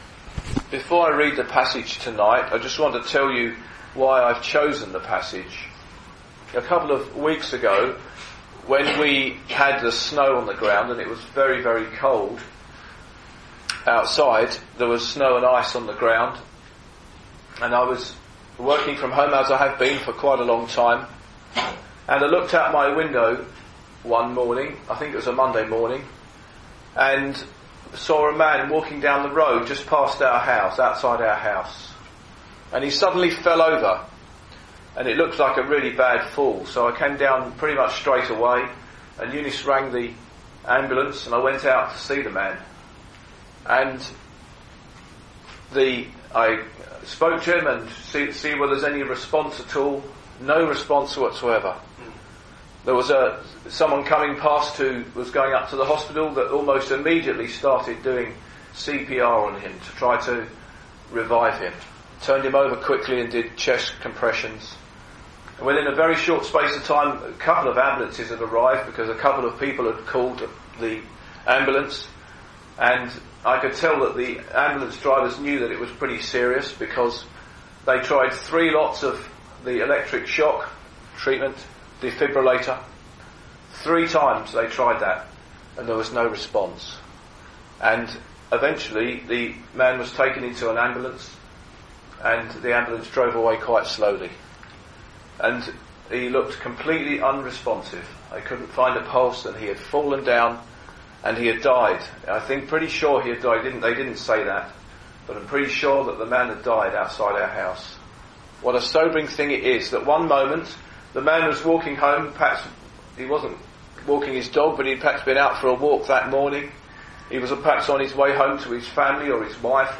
The Bible tells us that Jesus was the marked out Son of God in power. In this Gospel preaching, you will hear an account of the power Jesus had over death when he raised Lazarus from the tomb.